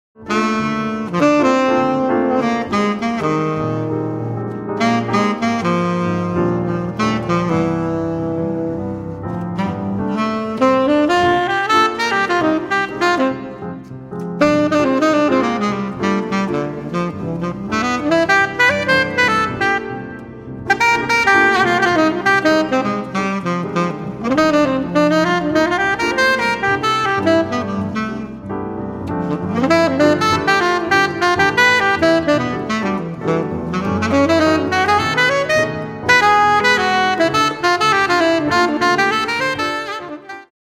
straight ahead swinging duo
saxophone
piano